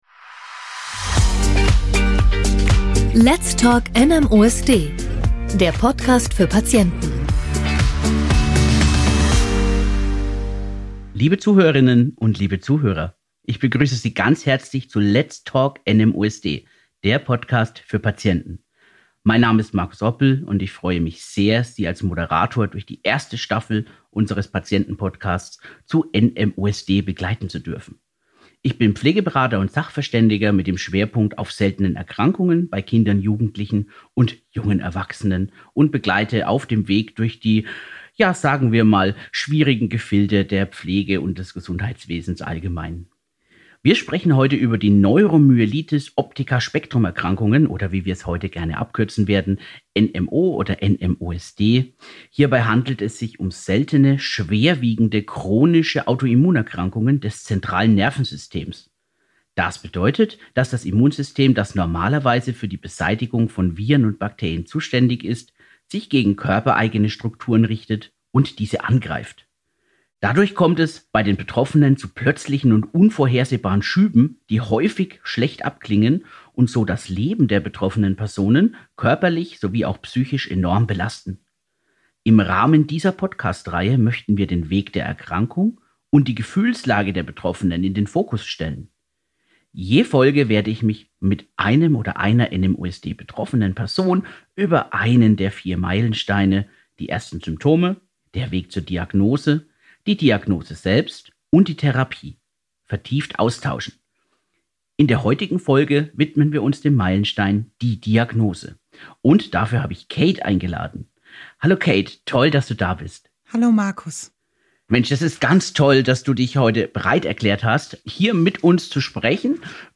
spricht mit einer Betroffenen, die uns persönliche Einblicke in ihre Reise mit NMOSD gibt.